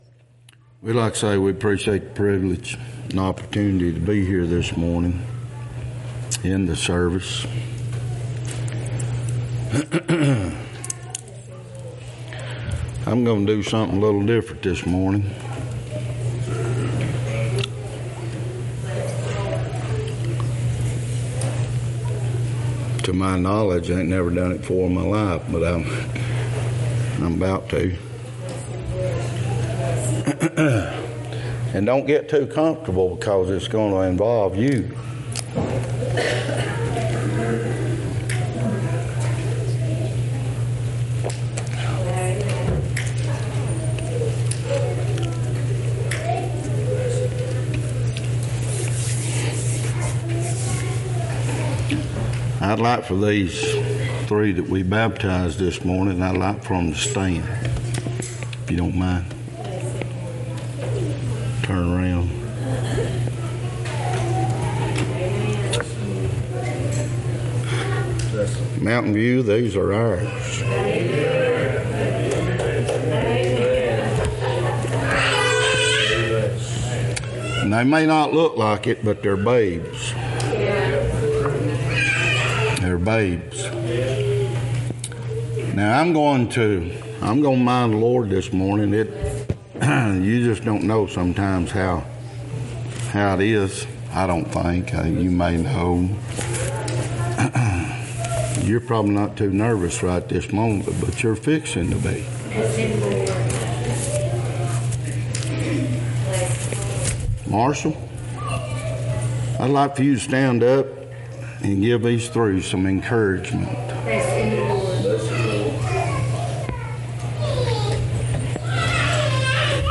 2025 Passage: Matthew 3:1-17 Service Type: Sunday Topics